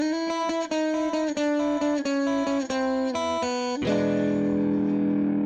蓝调A大调
标签： 88 bpm Blues Loops Guitar Electric Loops 939.68 KB wav Key : A
声道立体声